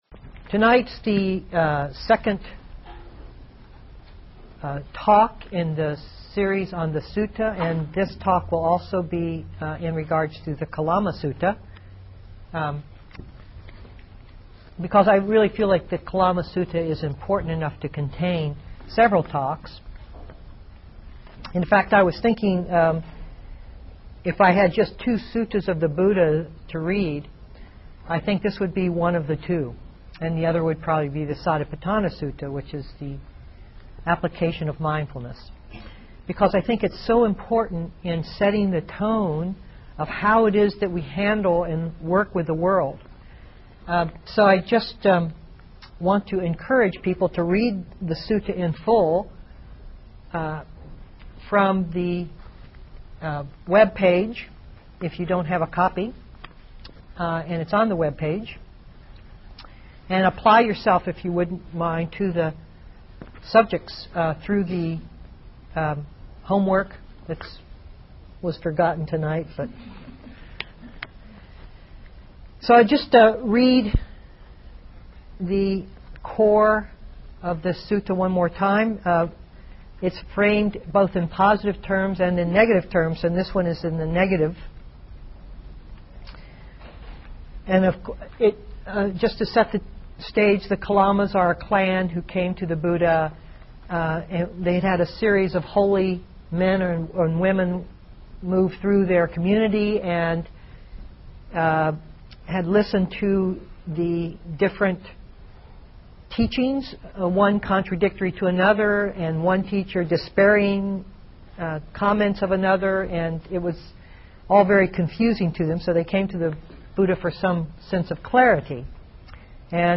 2002-09-30 Venue: Seattle Insight Meditation Center